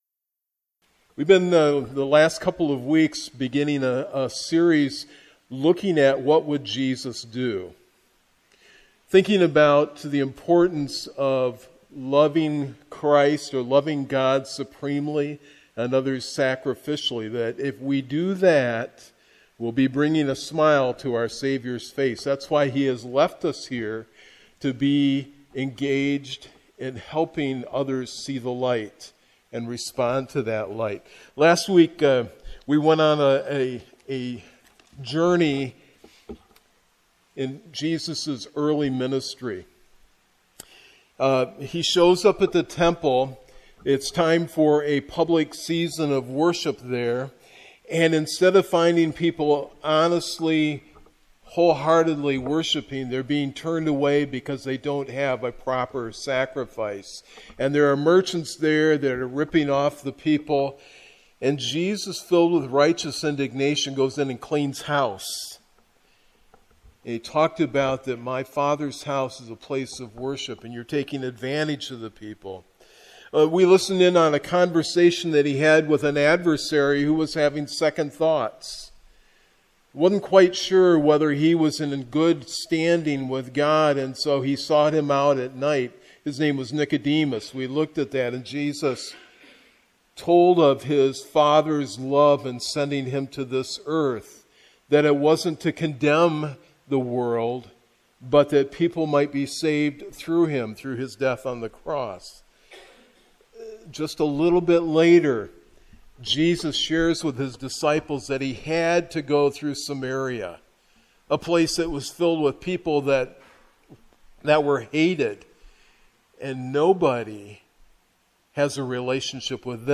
Sermons | Bethany Baptist Church
Guest Speaker